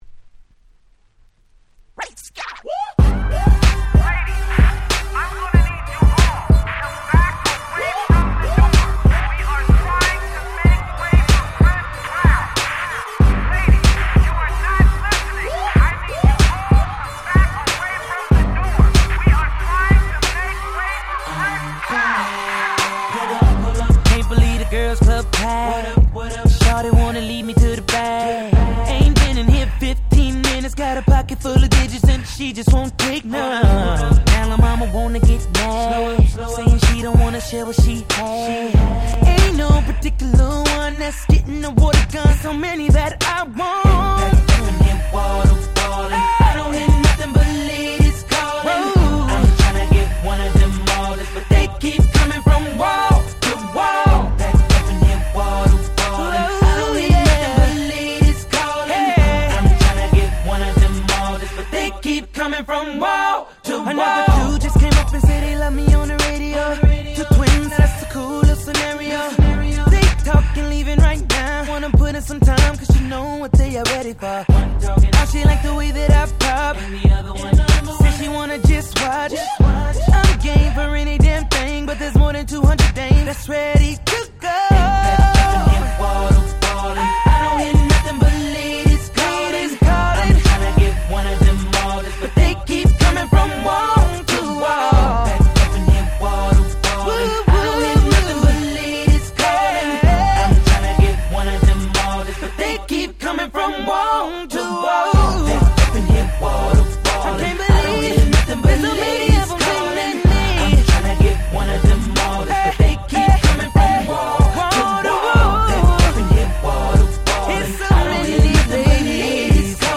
07' Smash Hit R&B !!